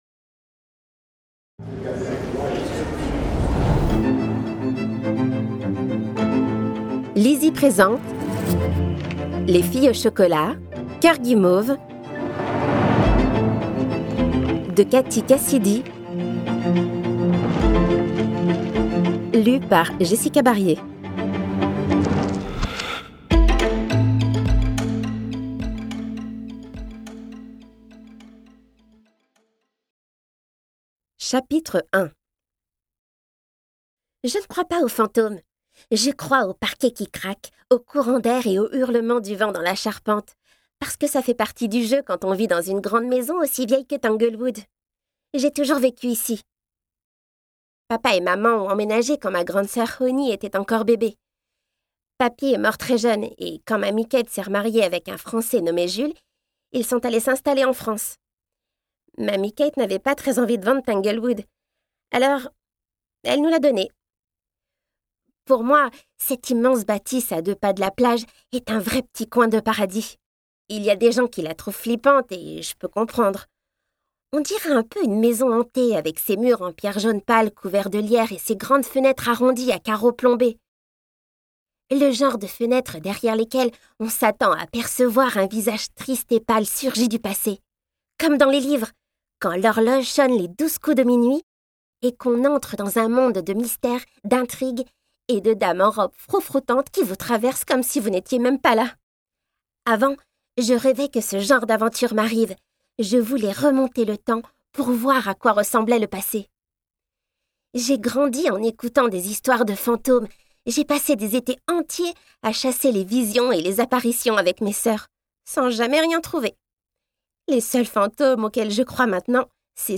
Les filles au chocolat : la série à succès enfin disponible en audio ! Je m'appelle : Skye Tanberry Mon âge : 12 ans Je suis : sentimentale et passionnée